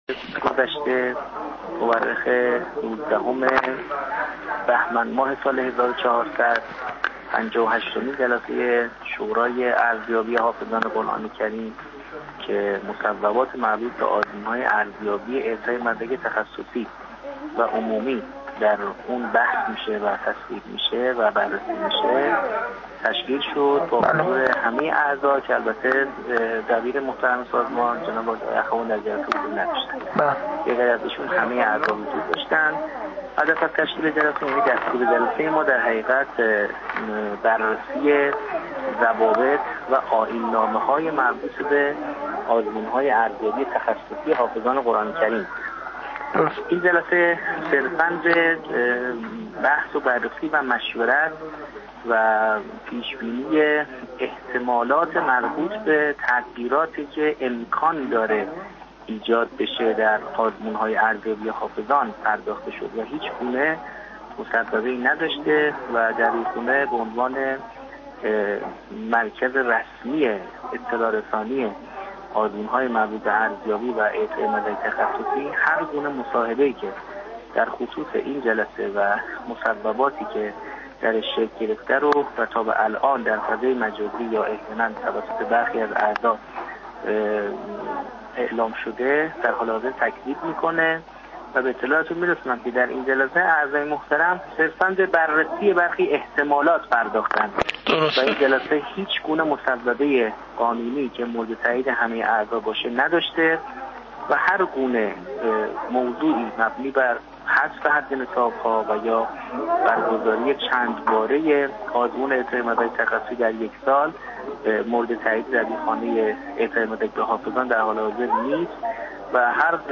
گفت‌وگویی